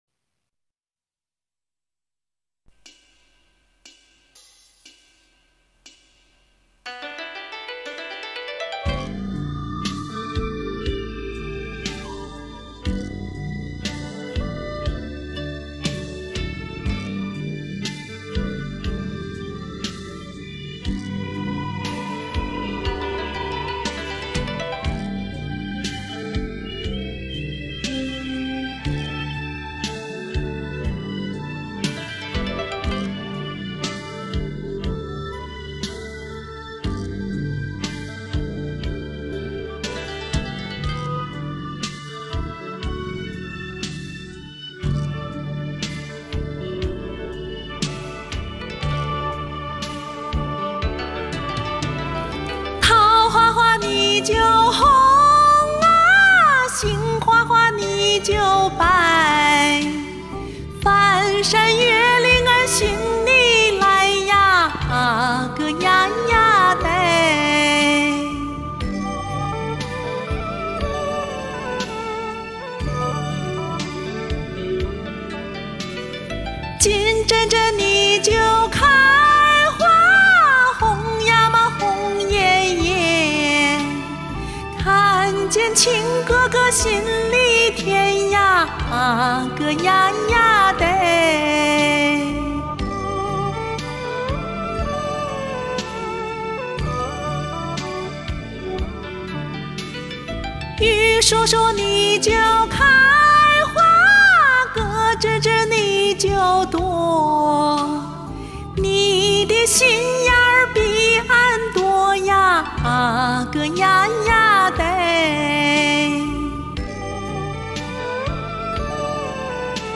他给我留了这首歌的作业，然后我自己其实也没啥信心，但是最后唱完我自己都很意外，怎么有点“原生态”的意思：P